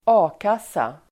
Ladda ner uttalet
A-kassa substantiv, unemployment benefit society (fund) Uttal: [²'a:kas:a] Böjningar: A-kassan, A-kassor Definition: arbetslöshetskassa Förklaring: Kassan betalar ut ekonomisk ersättning till medlemmar som blivit arbetslösa.